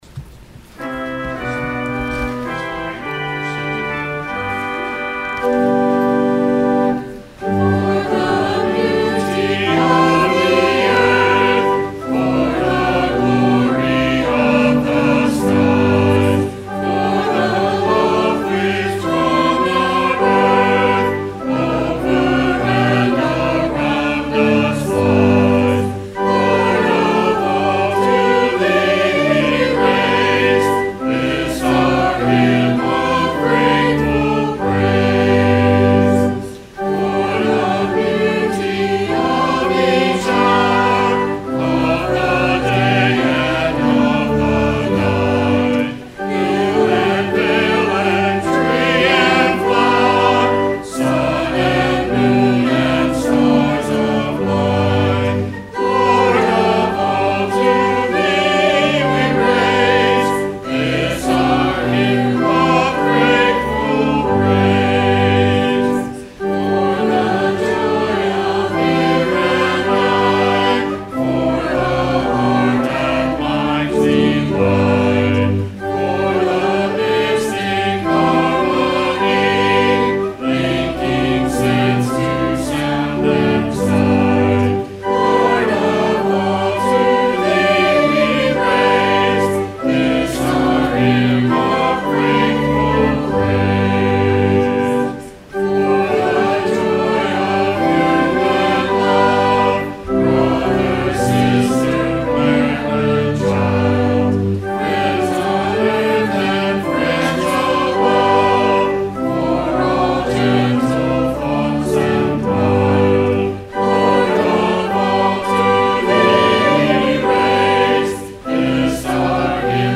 Sung by the Church and Choir.
Hymn